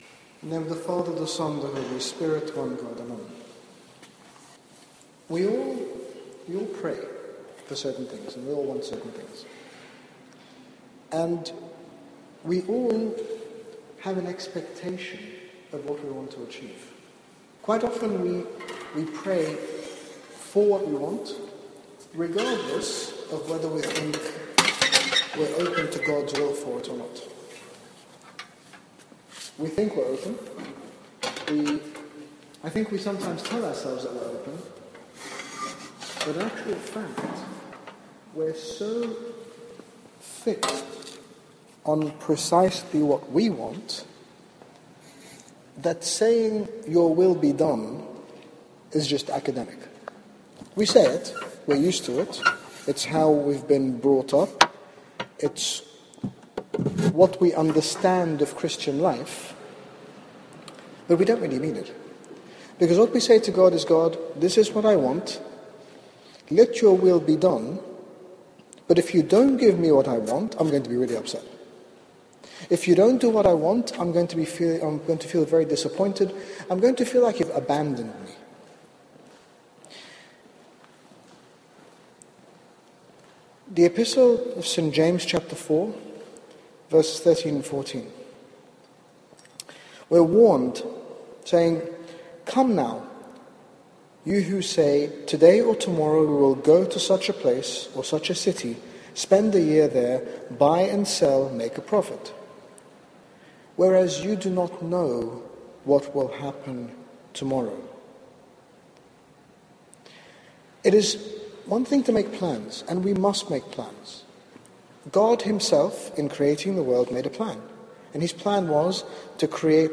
In this reflection, His Grace speaks about our willingness to allow God to work in our lives, trusting in His promise that He desires to give us a 'future and a hope' (Jeremiah 29:11) and that His plan for us will always be far better than any plan that we have for ourselves. This talk was given at the Grapevine Fellowship Meeting in London, UK.